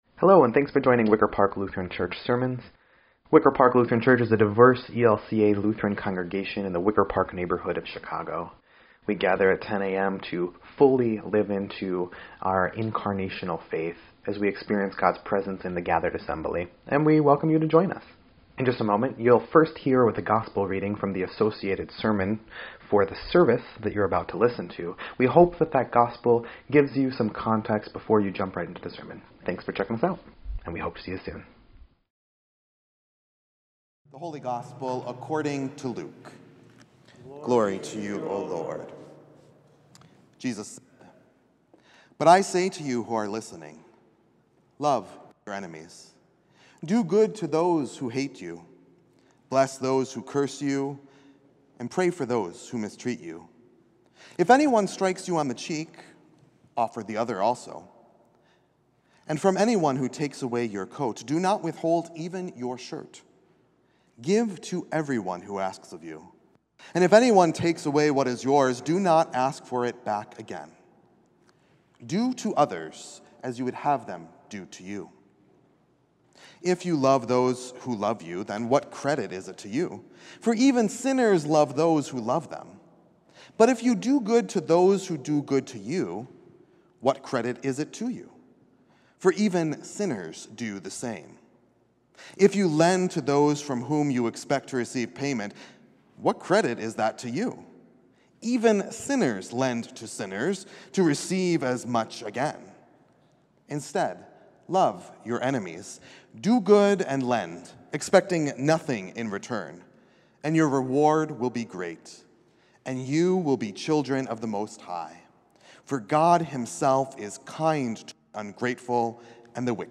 2.23.25-Sermon_EDIT.mp3